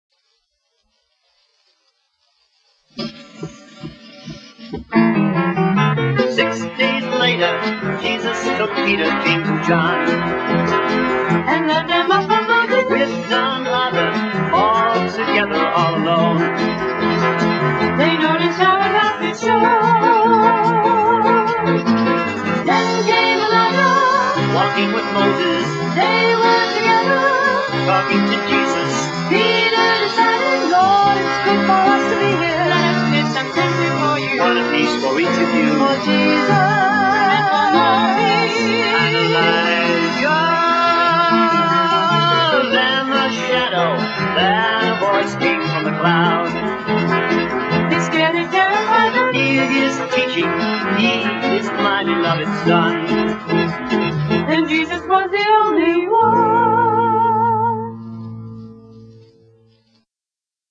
VOCALISTS
ORGAN, SYNTHESIZER
GUITAR, SYNTHESIZER
BASS GUITAR
DRUMS